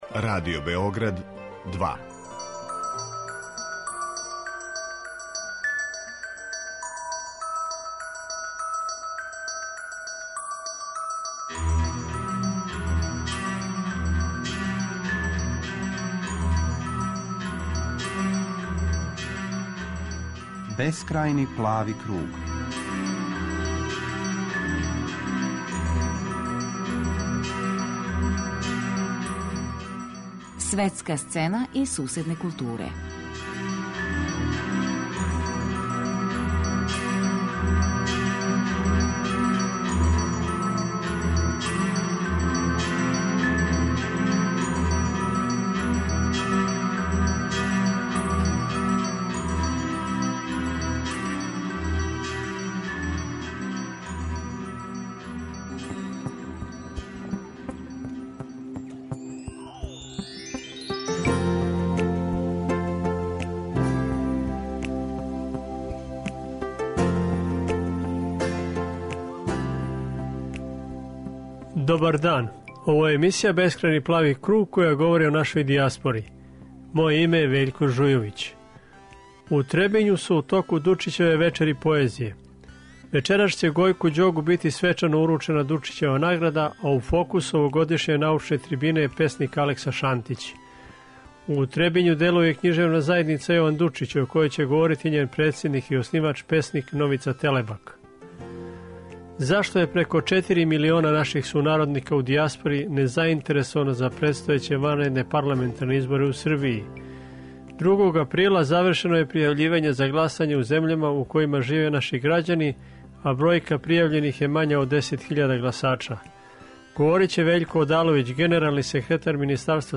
Емисија о дијаспори